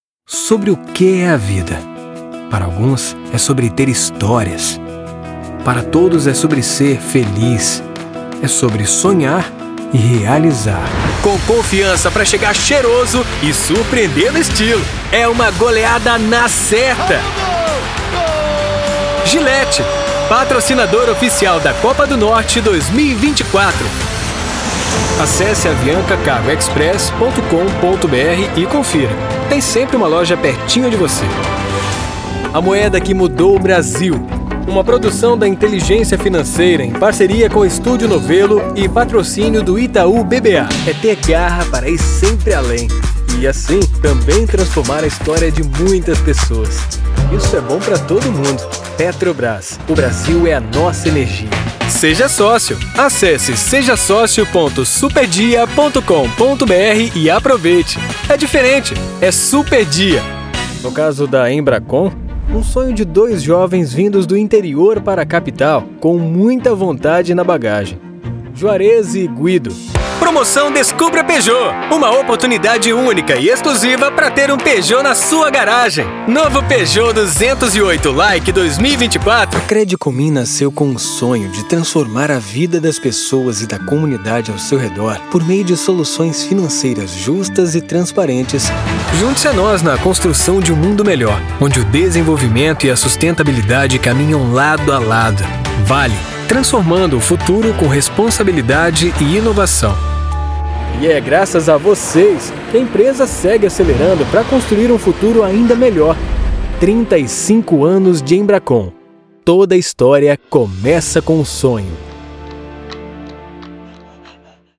Pleasant baritone, natural & conversational - Aggressive over-the-top "Truck Rally" delivery - Character voices - Radio announcer/personality - Audiobook Narration...
English (North American) Adult (30-50) | Older Sound (50+)